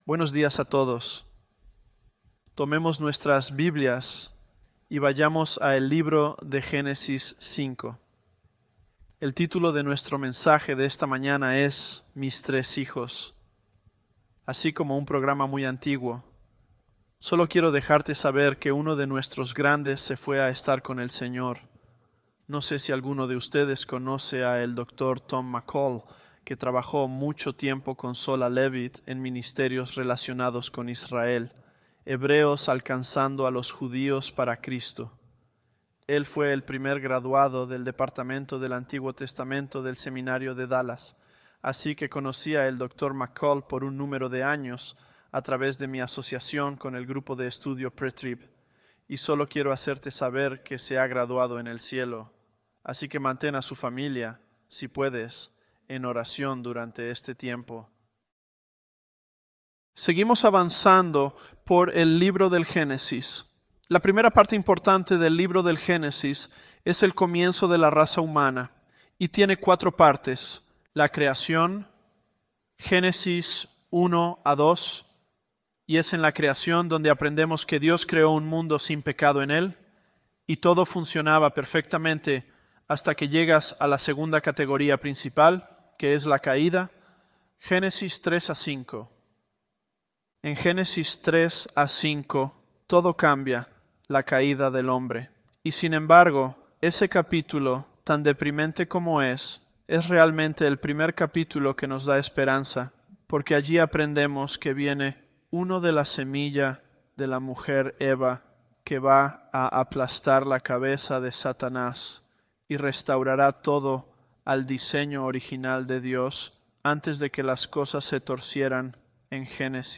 ElevenLabs_Genesis-Spanish024.mp3